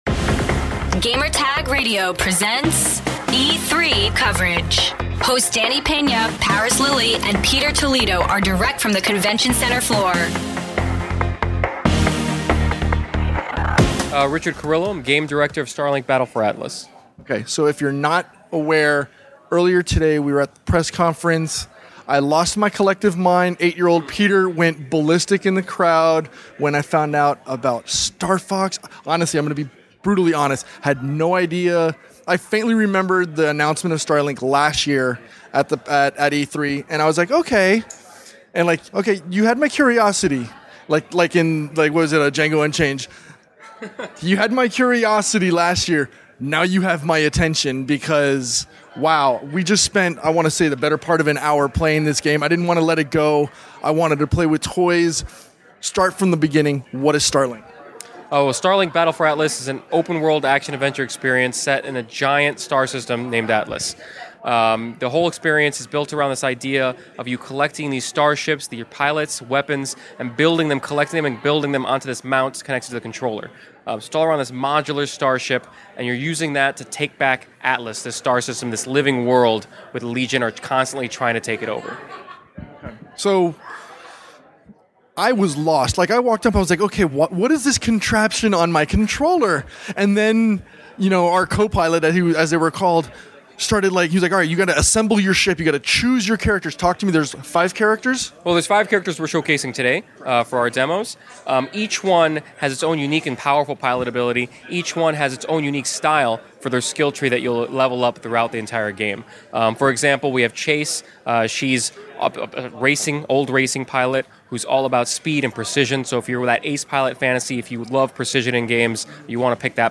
E3 2018: Starlink: Battle for Atlas Interview